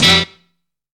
START STAB.wav